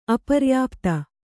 ♪ aparyāpta